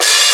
DDW6 OPEN HAT 5.wav